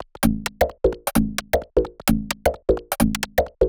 Tailor 130bpm.wav